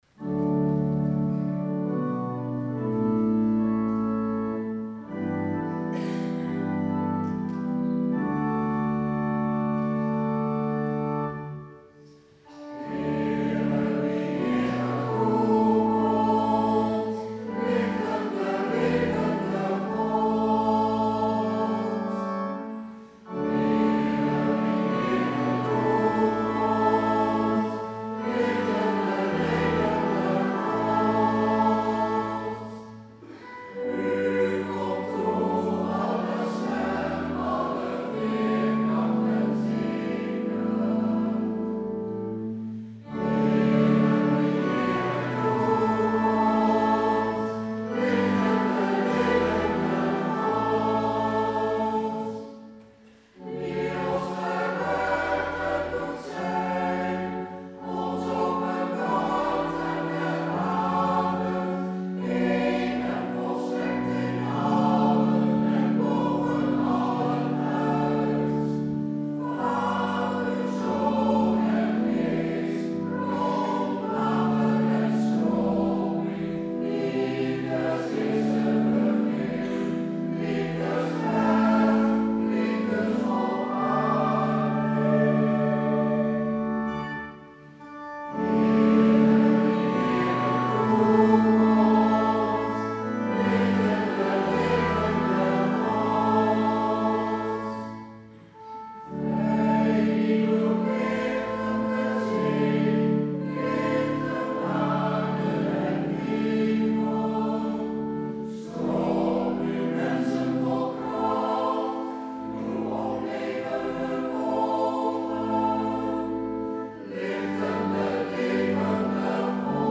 Oecumenische viering in de H. Nicolaaskerk
Lofzang: